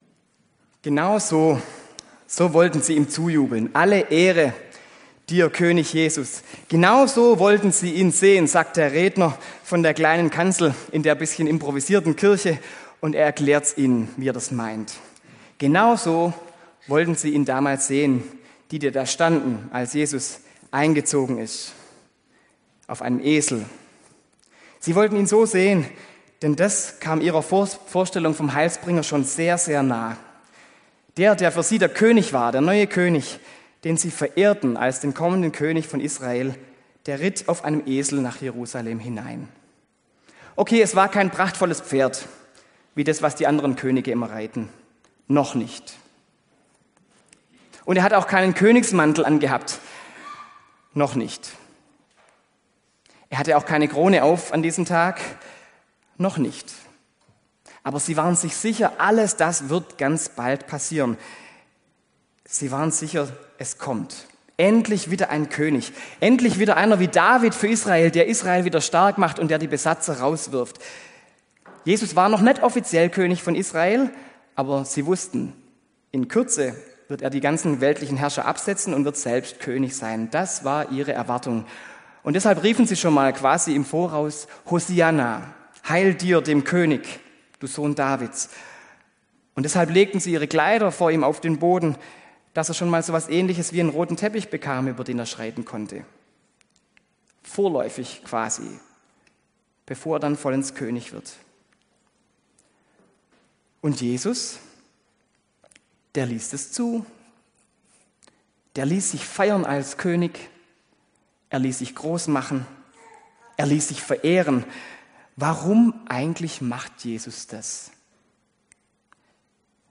Predigt an Palmsonntag: Der Undercover-König
predigt-an-palmsonntag-der-undercover-koenig